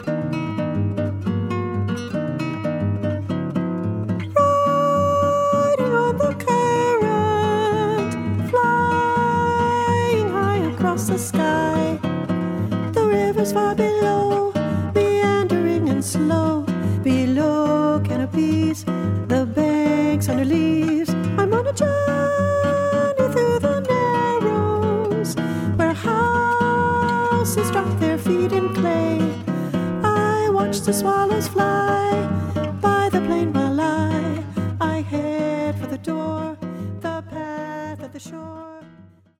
so uplifting and flowing